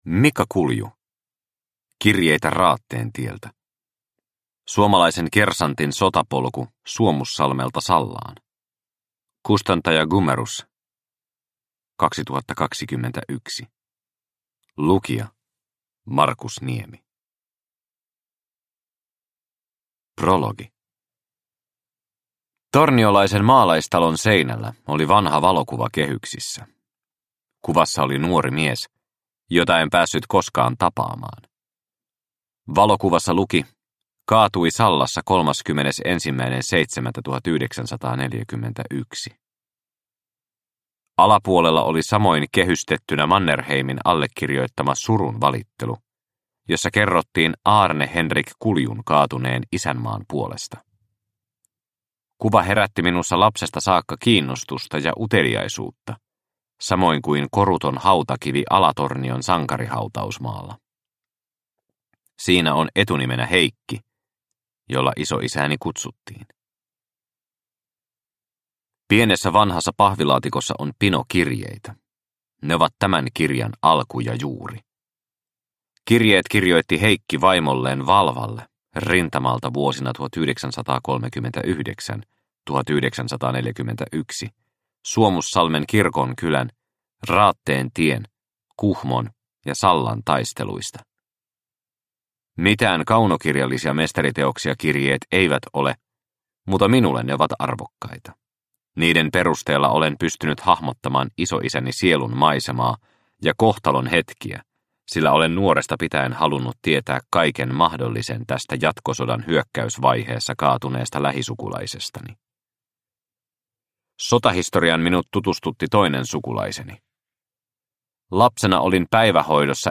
Kirjeitä Raatteen tieltä – Ljudbok – Laddas ner